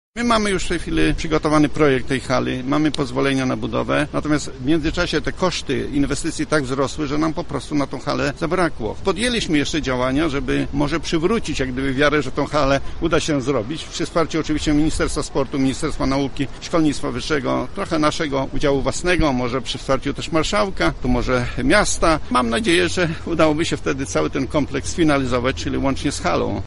Do ukończenia inwestycji brakuje jeszcze od 30 do 40 milionów złotych.  Rektor UMCS, Stanisław Michałowski przyznał, że uczelnia będzie szukała wsparcia w kilku źródłach.